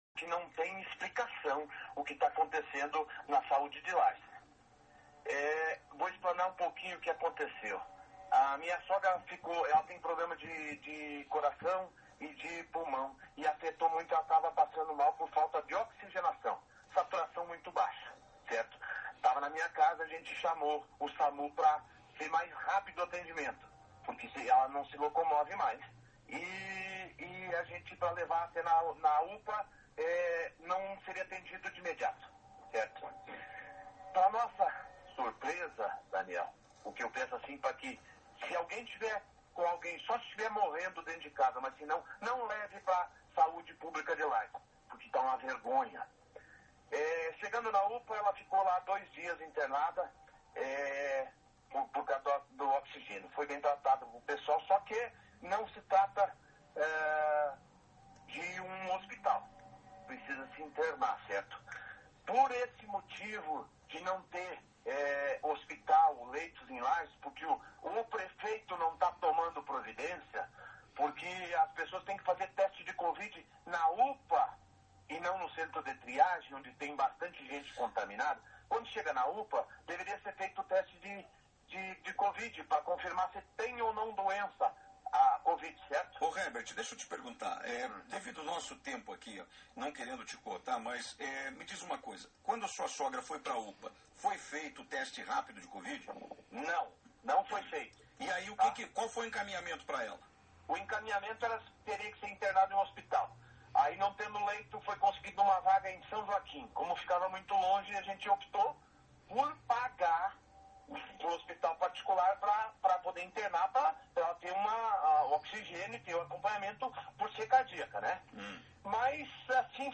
Relato feito no programa Clube Repórter, nesta terça-feira